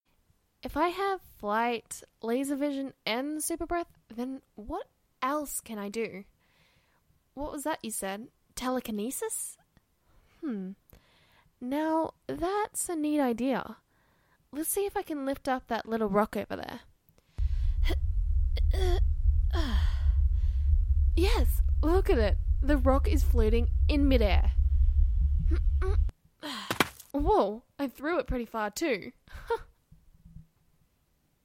(story spoiler heavy) – She lists some of the powers she has and uses a rather impressive one WITH effects
(Please note the lines were individually recorded and spliced together)